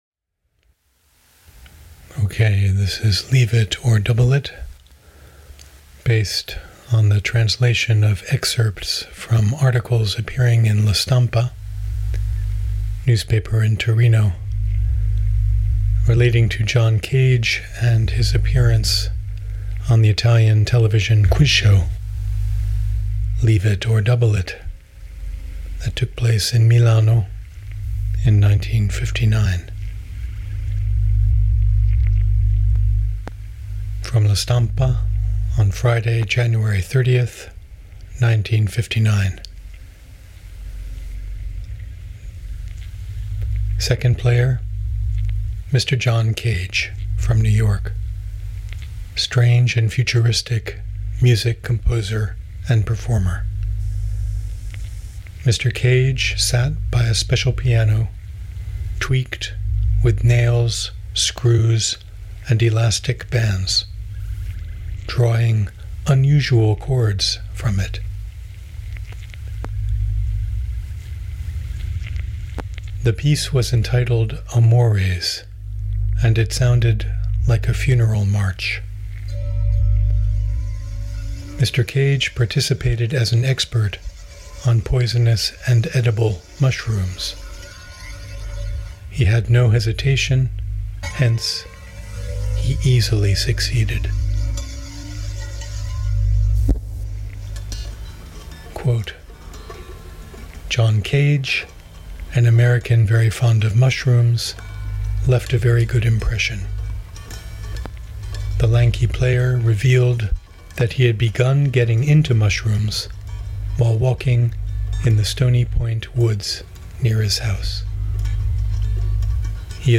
On this, the John Cage Centennial, I offer Leave it or Double it, a bit of radiophonic fungus produced on invitation from Transmission Arts, with its premier broadcast on WGXC a few days ago.
In fruiting the fungus, all I knew from the outset was that I would aim for a duration of 33:33, and that I would use translated excerpts from the Turin newspaper La Stampa as source material – reviews regarding the 1959 appearance of a young American composer named John Cage on a very popular Italian television quiz show, Lascia o Raddoppia. I was careful not to practice or rehearse the texts in any way, but to confront them in a single take, with no way to correct mushroom pronunciation mistakes.